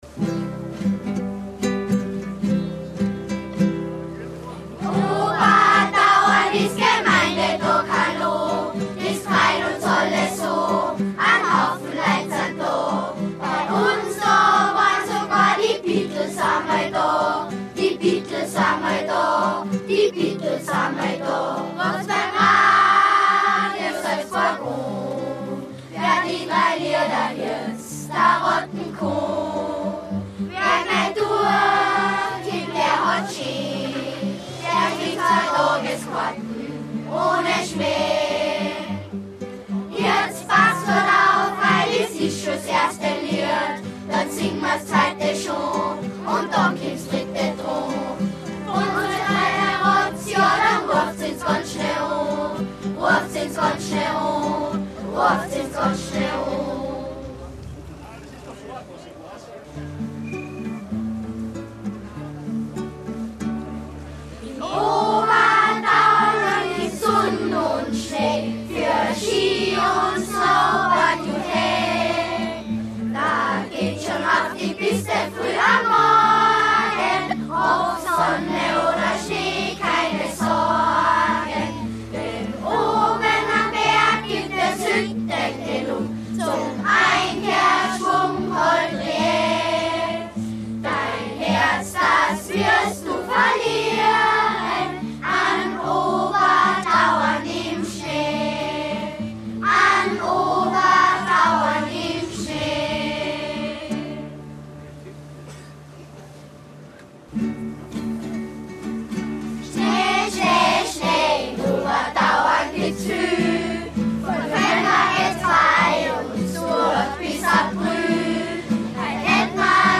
Gemeindetag
Der ORF – Ladesstudio Salzburg – sendete an diesem Tag live aus Obertauern und berichtete aus den Gemeinden Untertauern und Tweng.
Unsere perfekt einstudierten, selbst gedichteten Lieder, waren das Highlight der ORF Übertragung.
Lieder.mp3